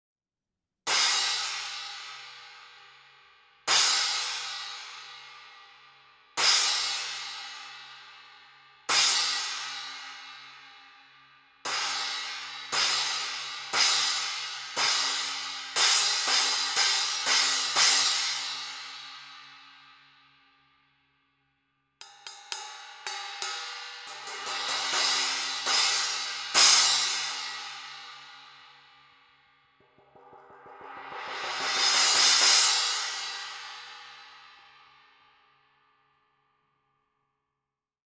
12″ Armor Holey Splash Cymbals (Approx 410 grams):
12__Armor_Holey-Splash.mp3